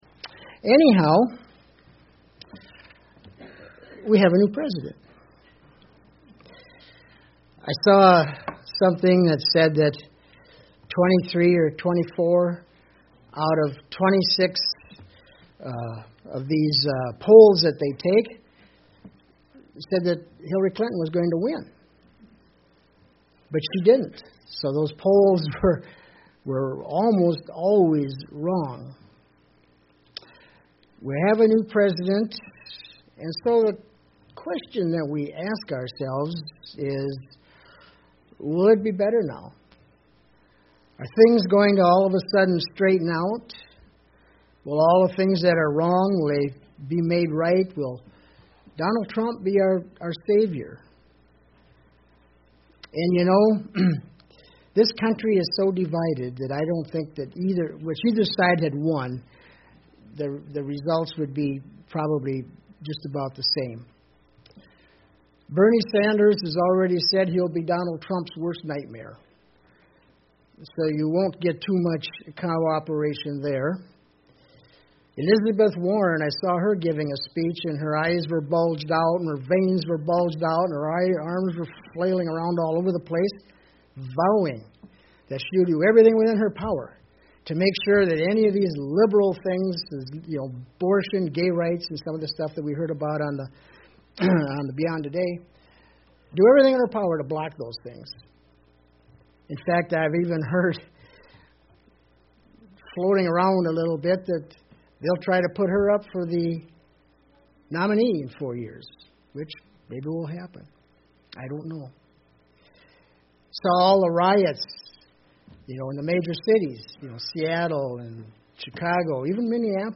Given in Southern Minnesota